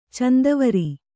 ♪ candavari